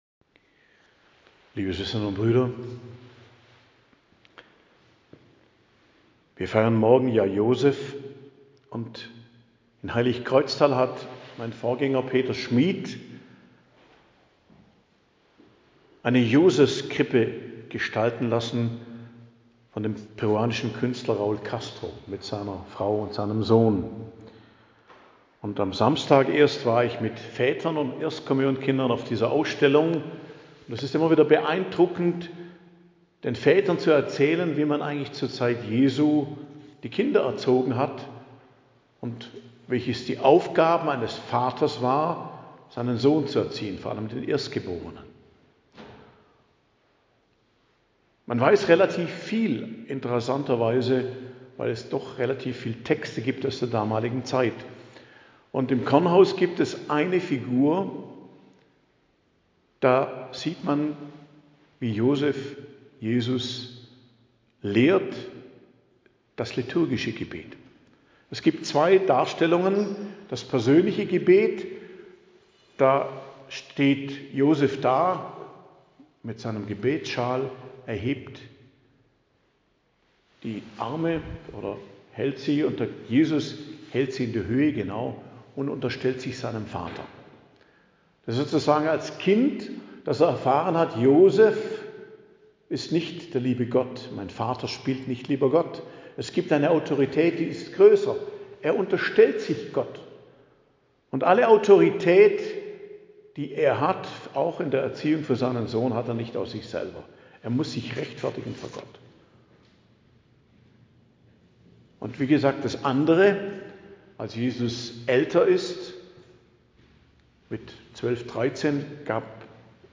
Predigt am Dienstag der 2. Woche der Fastenzeit, 18.03.2025 ~ Geistliches Zentrum Kloster Heiligkreuztal Podcast